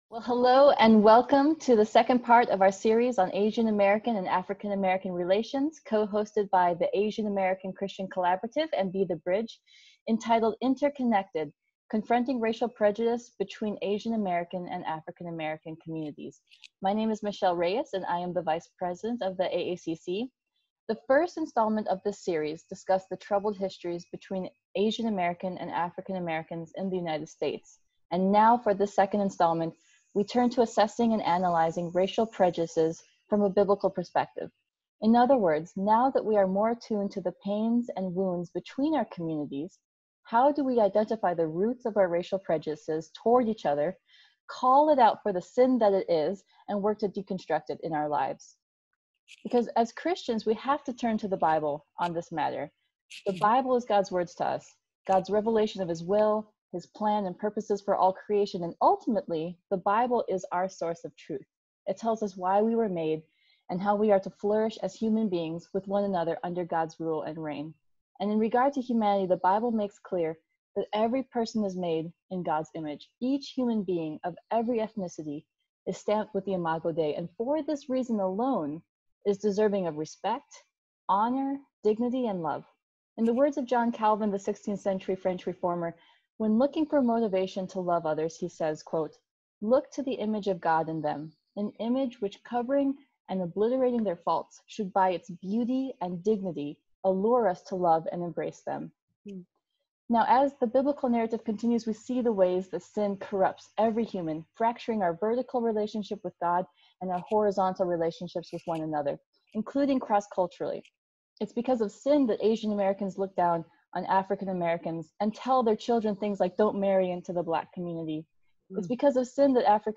Genre Discussion Panel